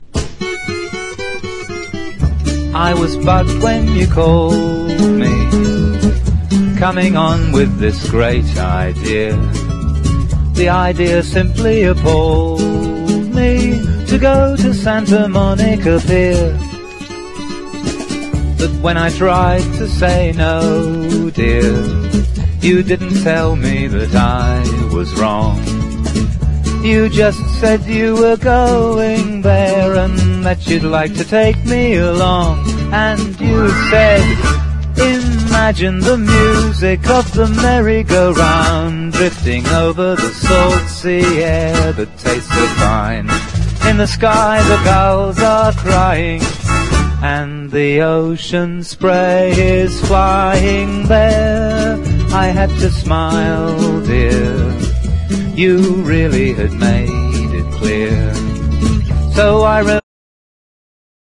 ROCK / S.S.W./A.O.R. / PIANO MAN / COMEDY
、終始ふざけっぱなしの愉快すぎるライブ録音！